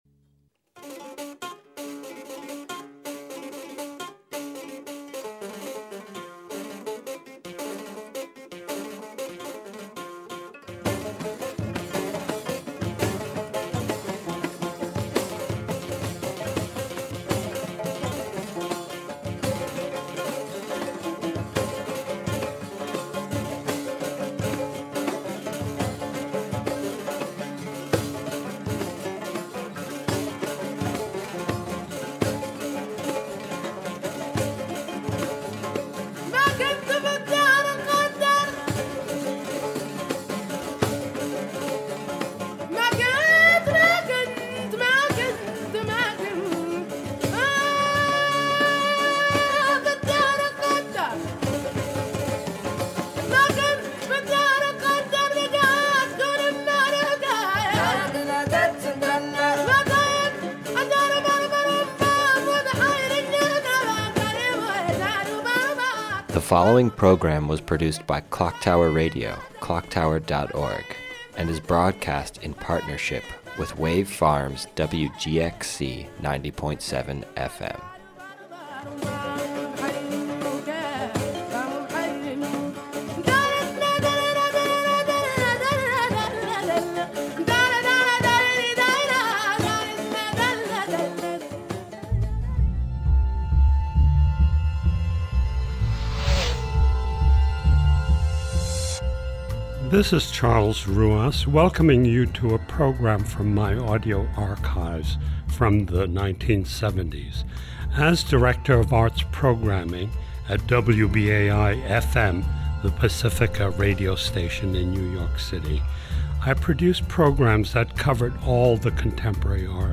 Interspersed throughout the recording, Haley reads ffrom the novel.